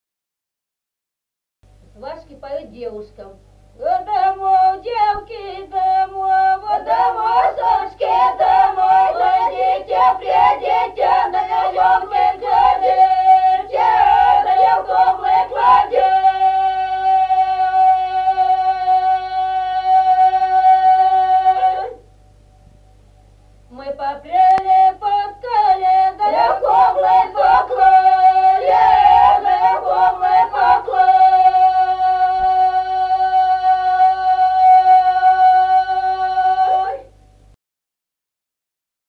Народные песни Стародубского района «До домов, девки», свадебная, свашки поют девушкам.
(запев)
(подголосник)
с. Остроглядово.